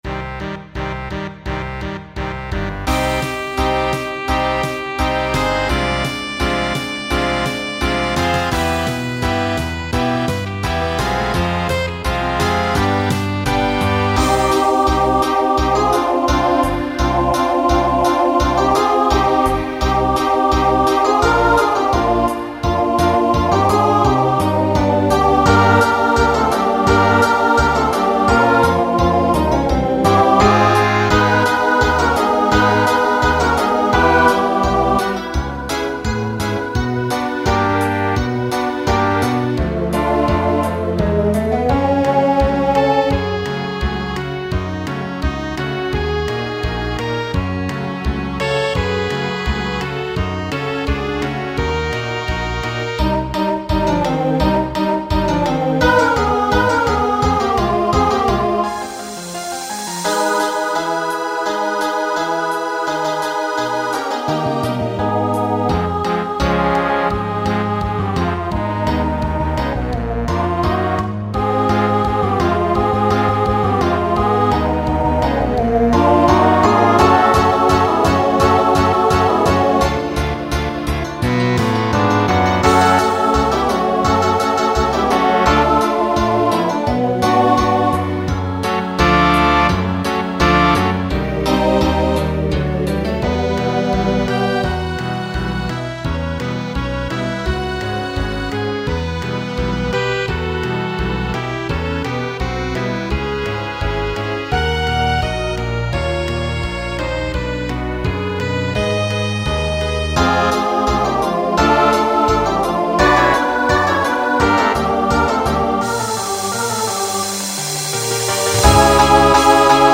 Voicing SATB Instrumental combo Genre Broadway/Film